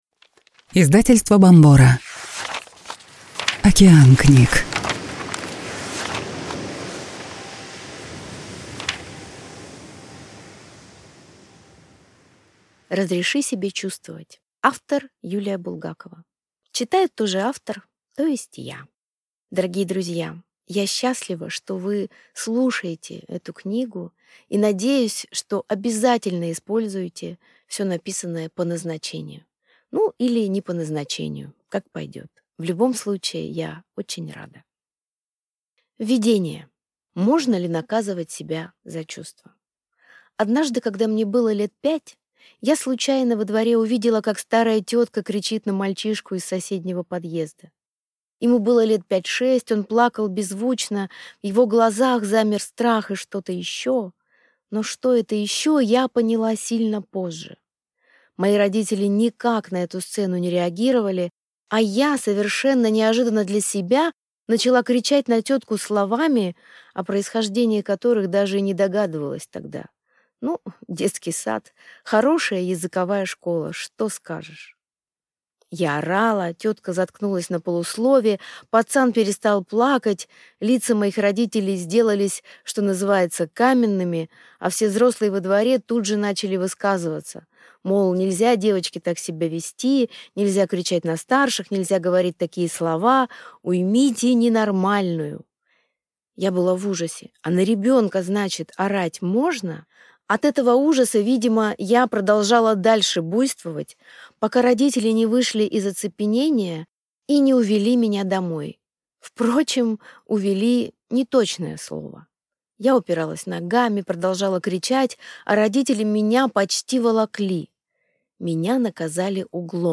Аудиокнига Разреши себе чувствовать. Как перестать подавлять себя и обрести подлинную силу | Библиотека аудиокниг